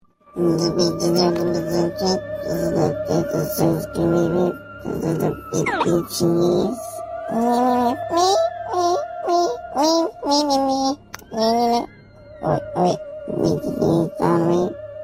Alien Mimimimi